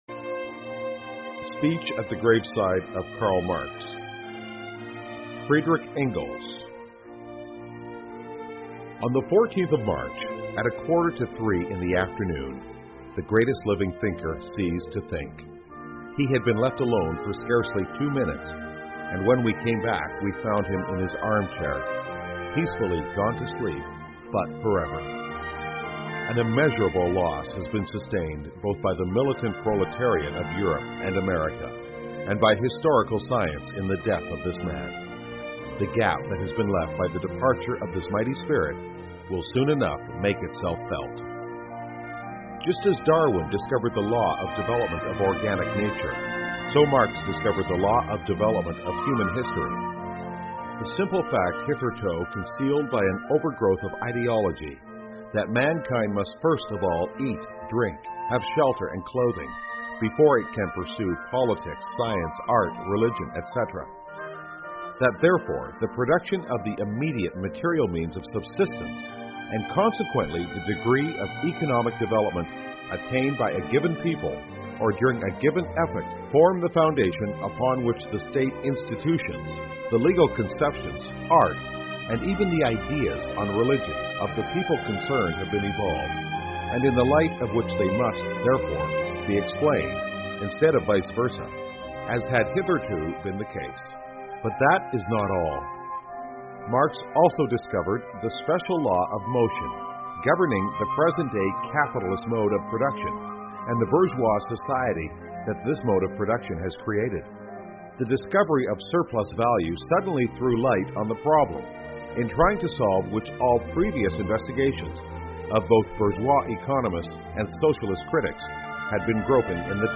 世界上最伟大的演讲--Engels's speech on Karl Marx's funeral 恩格斯:在马克思墓前的讲话 听力文件下载—在线英语听力室